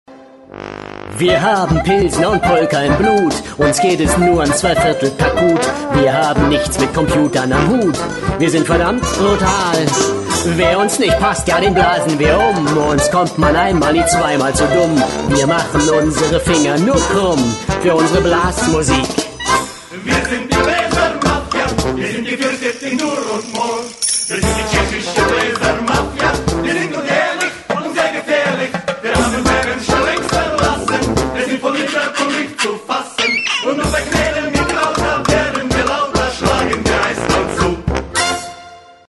Music for the people – World Music!